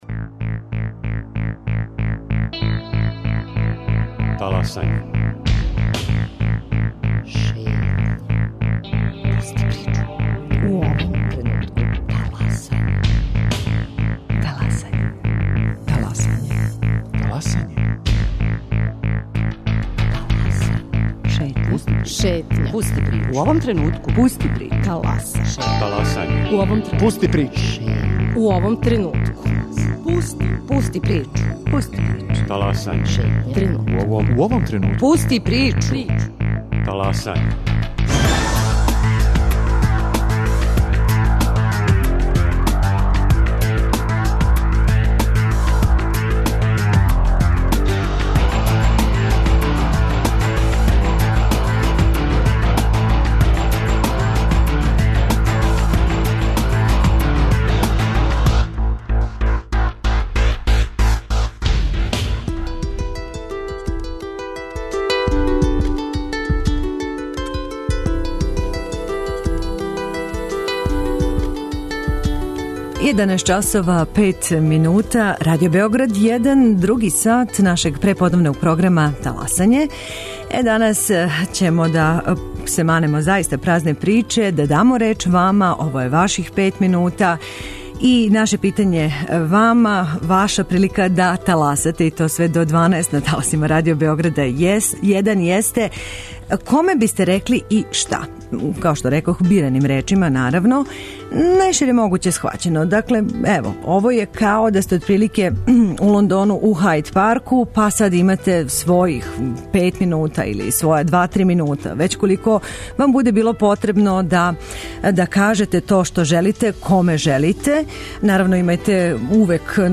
Од 11 до 12 часова на нашим таласима, у оквиру "Таласања", таласате - ви!